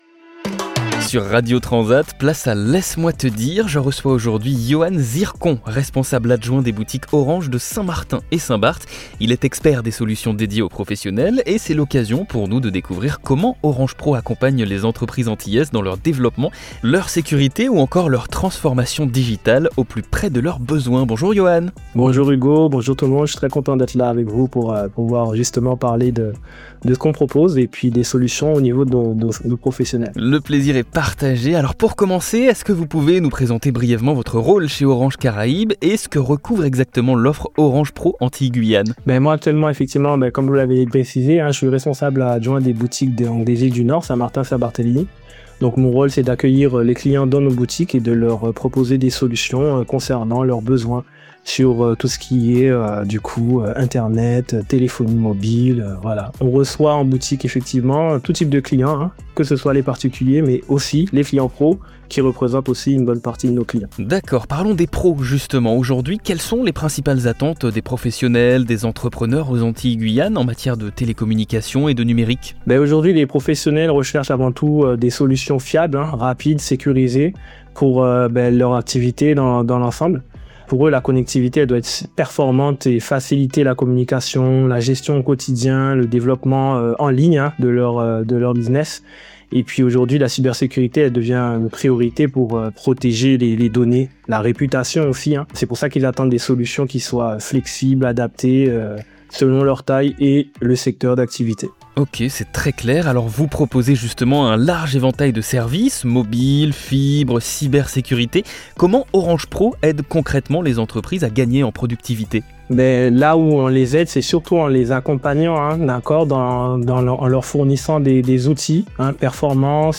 31 octobre 2025 Écouter le podcast Télécharger le podcast Dans cette interview